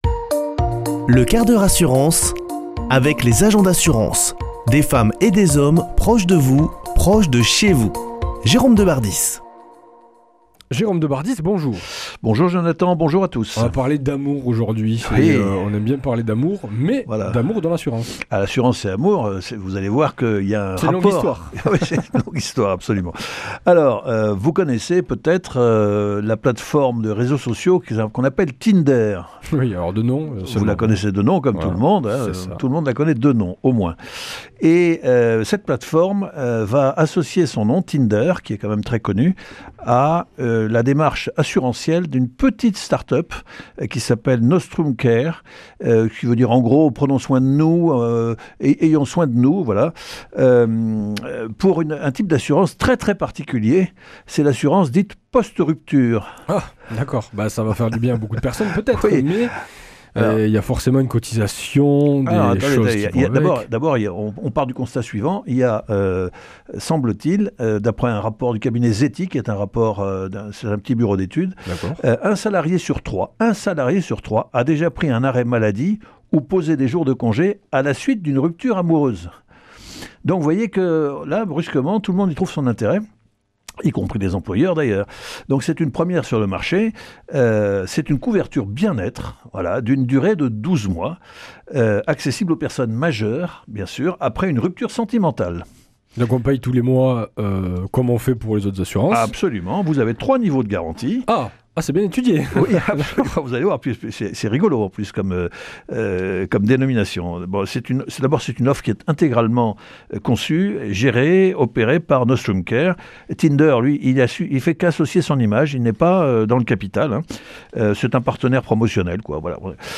mardi 24 février 2026 Chronique le 1/4 h assurance Durée 5 min
Chroniqueur